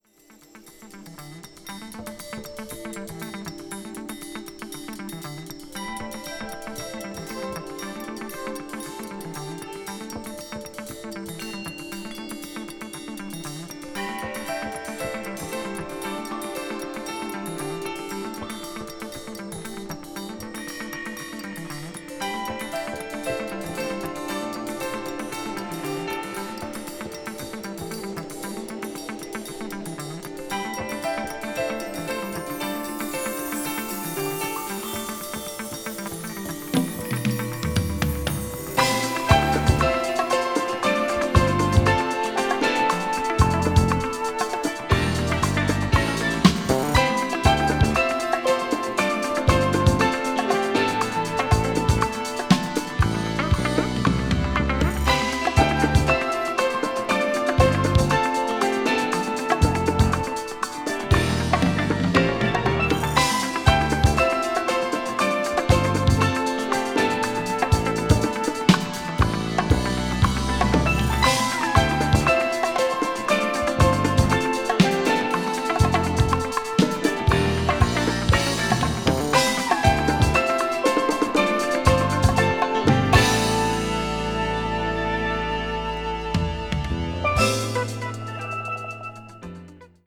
crossover   ethnic jazz   fusion   jazz groove   tropical